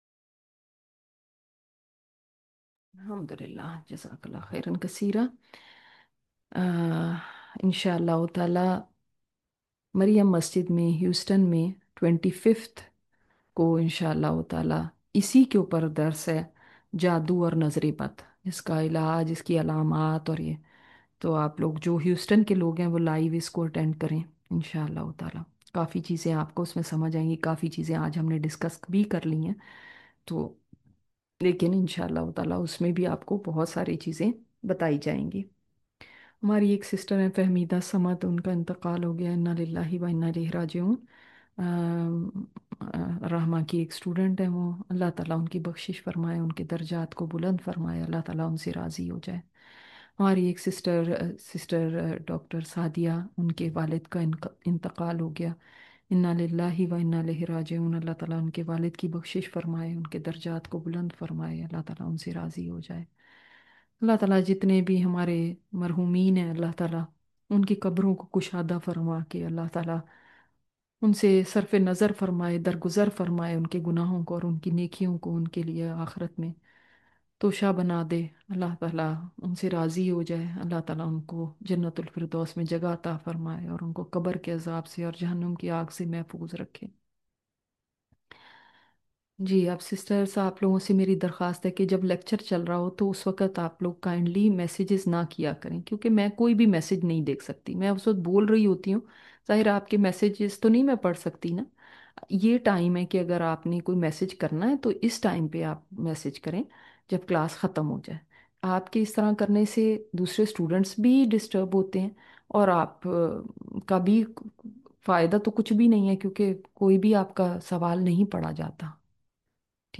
Latest Lecture